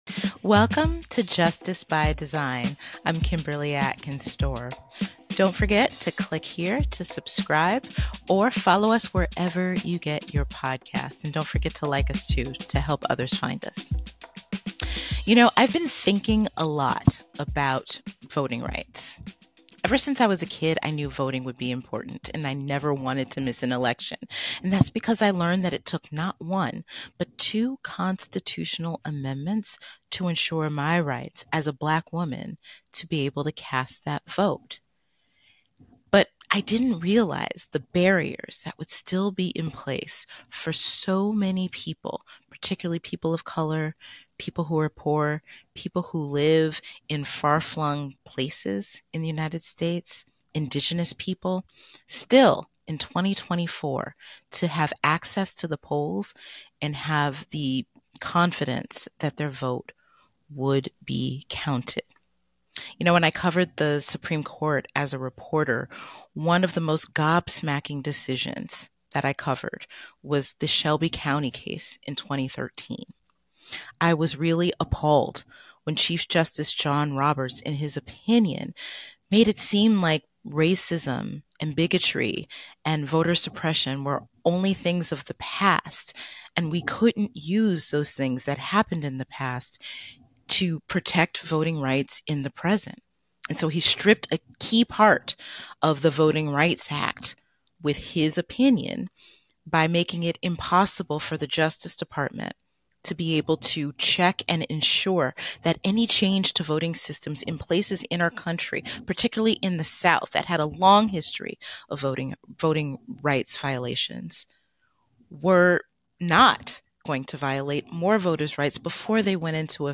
Kimberly Atkins-Stohr interviews Marc Elias, the founder of Democracy Docket, about the importance of protecting voting rights and the challenges faced in doing so. They discuss the dangerous rhetoric of former President Donald Trump and the need to take his words seriously.